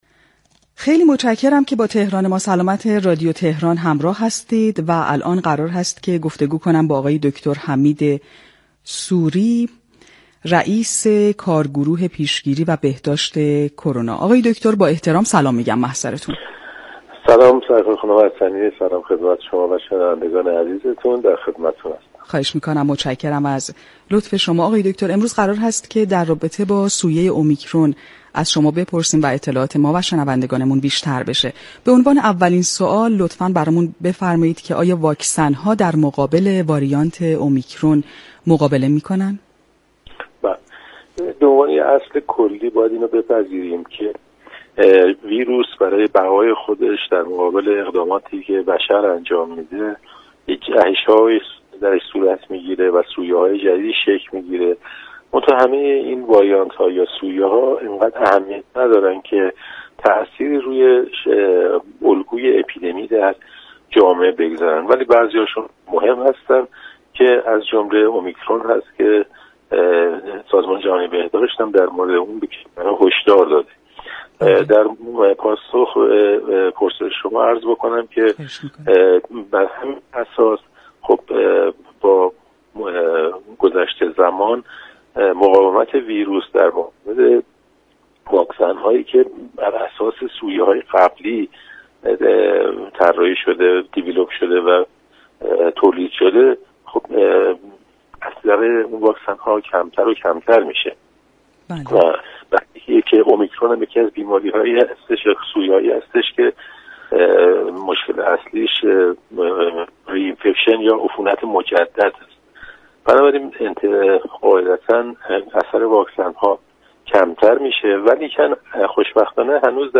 در گفتگو با برنامه تهران ما سلامت رادیو تهران